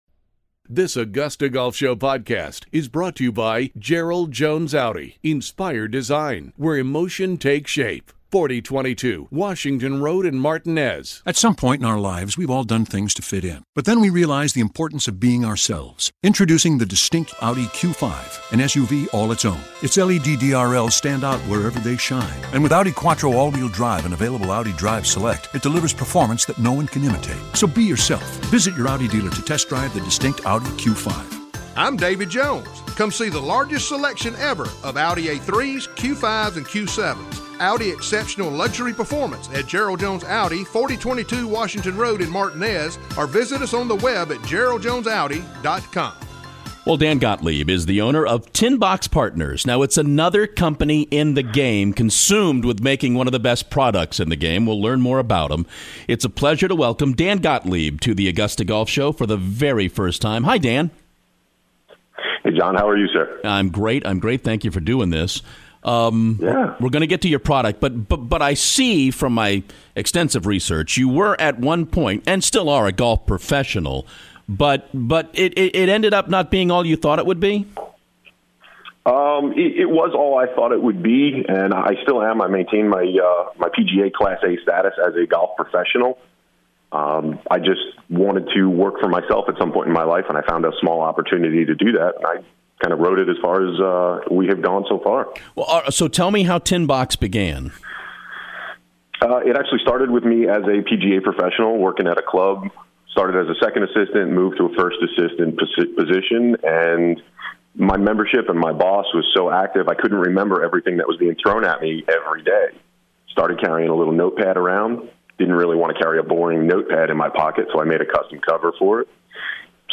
Tin Box Partners: The AGS Interview